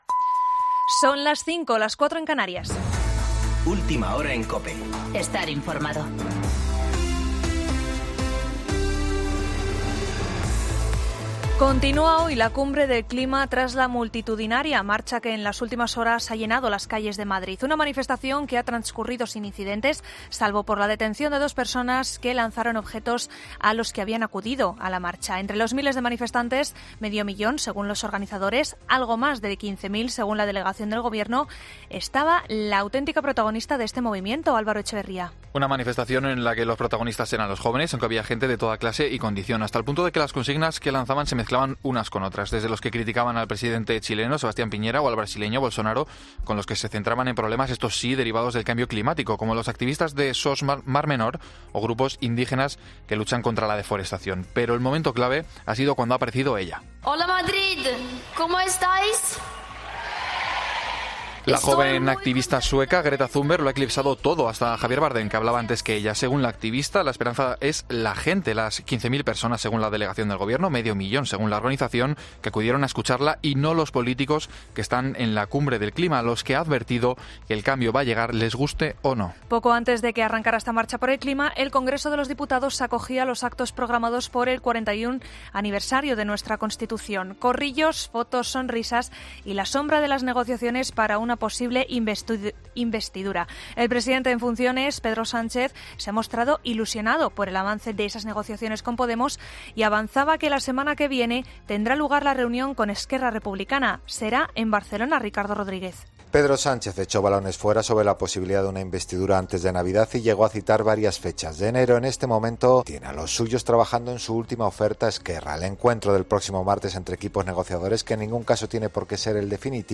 Boletín de noticias COPE del 7 de diciembre de 2019 a las 5.00 horas